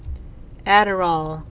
(ădər-ôl)